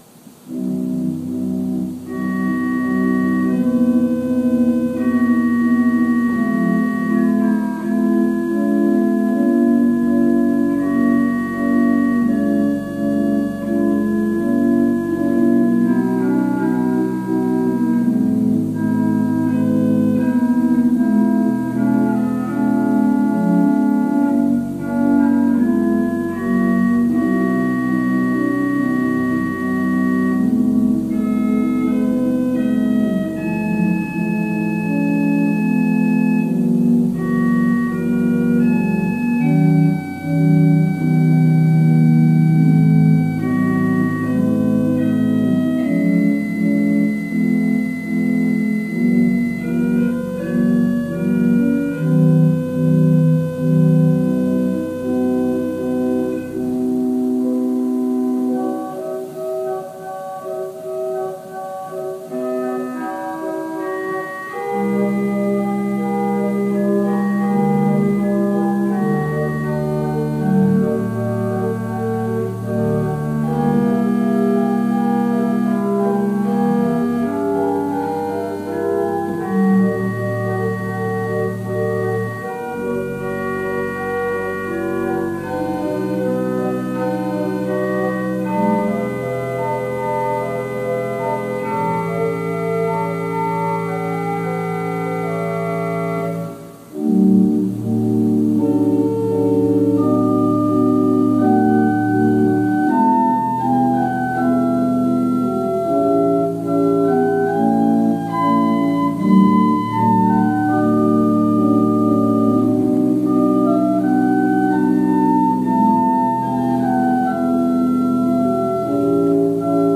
Worship Service February 7, 2021 | First Baptist Church, Malden, Massachusetts
Worship-Service-February-7-2021.mp3